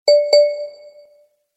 • Качество: Хорошее
• Категория: Рингтон на смс